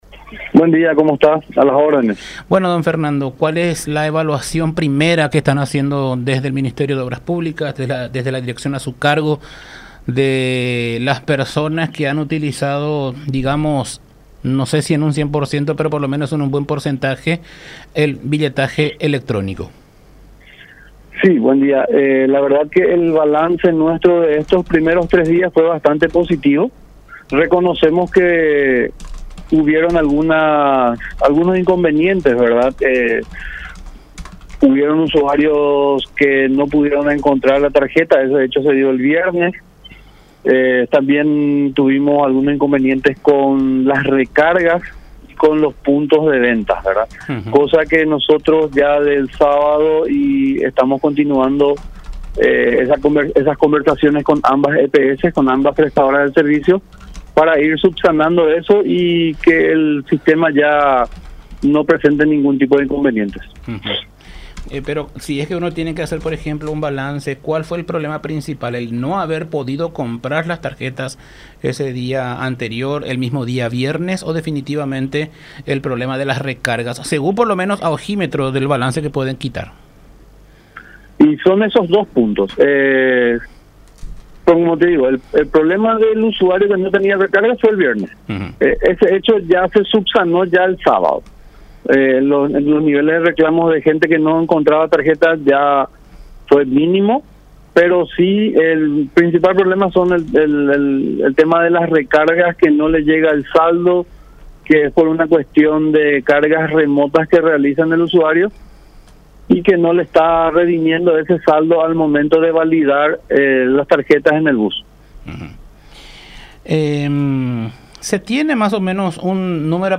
“El balance  nuestro de estos primeros tres días fue positivo, reconocemos que hubo algunos inconvenientes, usuarios que no pudieron encontrar la tarjeta, ese hecho se vio el viernes, también tuvimos algunos inconvenientes con las recargas con los puntos de ventas” sostuvo Fernando Haider, el director Metropolitano de Transporte del MOPC, en diálogo con La Unión R800 AM.